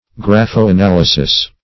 graphoanalysis \graph`o*an*al"y*sis\
(gr[a^]f`[-o]*an*[a^]l"[i^]*s[i^]s), n.